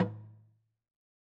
3-logdrum.wav